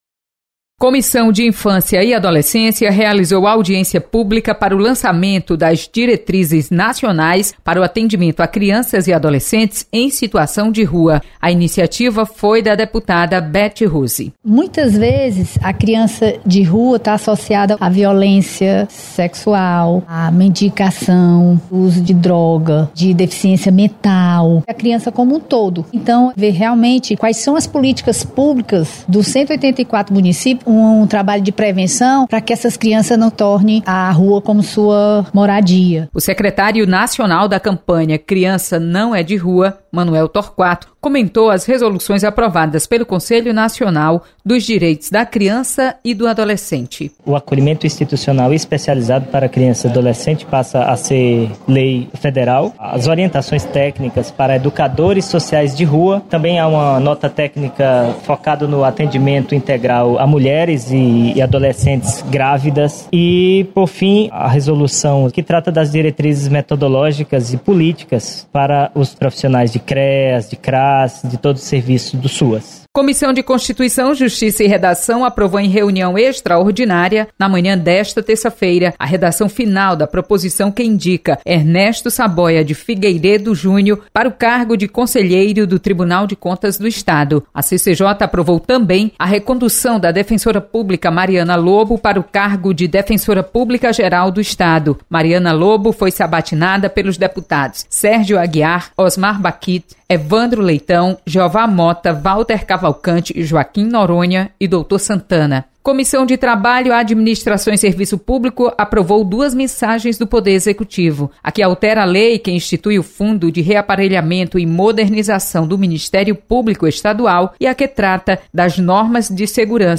Acompanhe o resumo das comissões técnicas permanentes da Assembleia Legislativa. Repórter